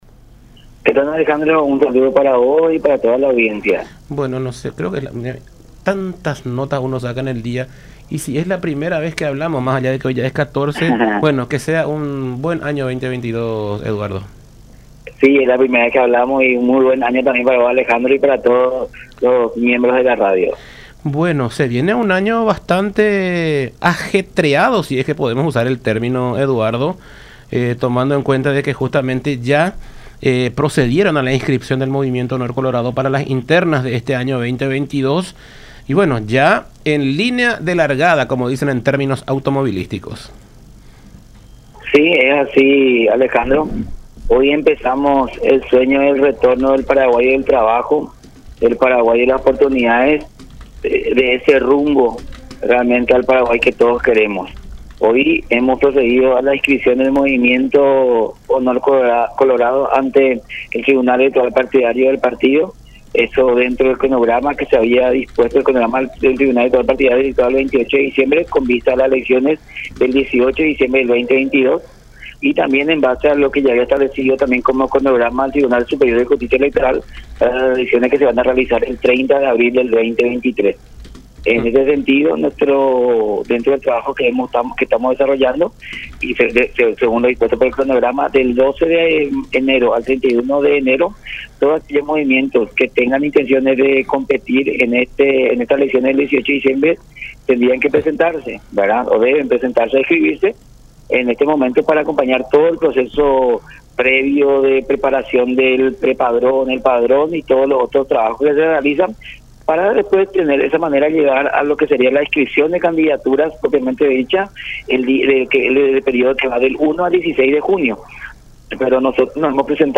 en conversación con Todas Las Voces por La Unión.